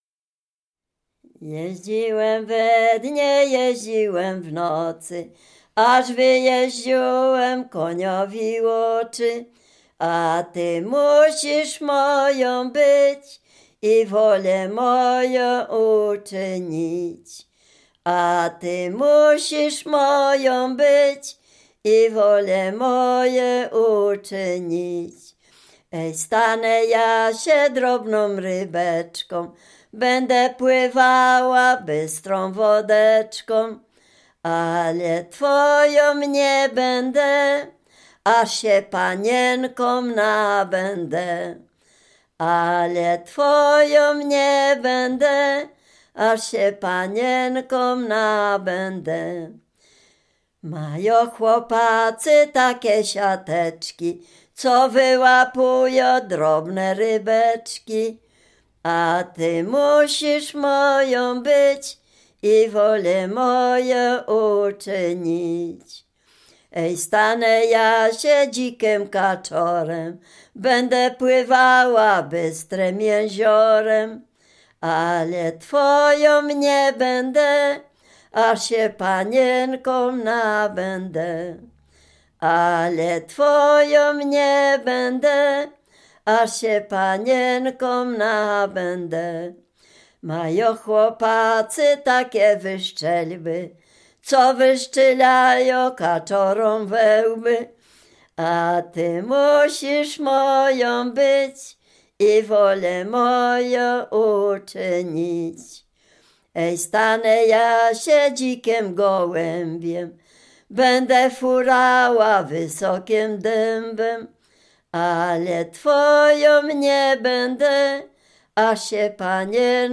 Dolny Śląsk, powiat bolesławiecki, gmina Nowogrodziec, wieś Zebrzydowa
liryczne miłosne